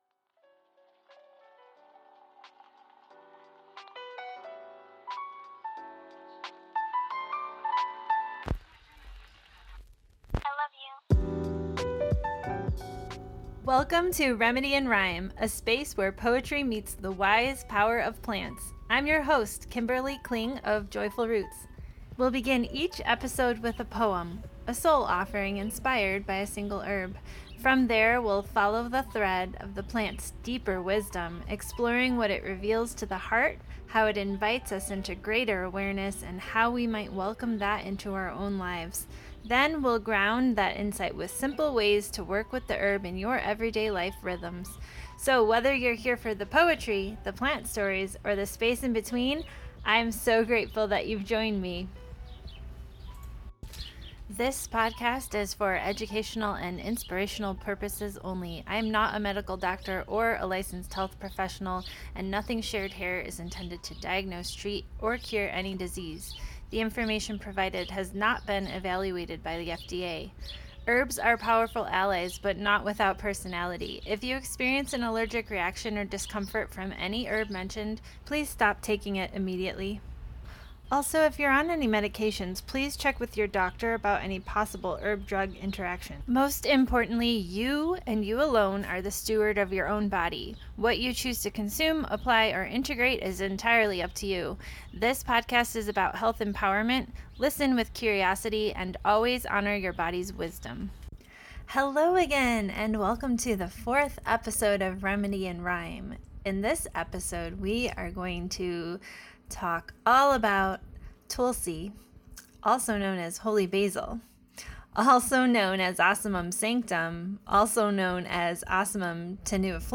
🌱 Each episode will begin with a poem and flow into a deeper dive into the featured herb—its stories, energetics, remedies, and reflections.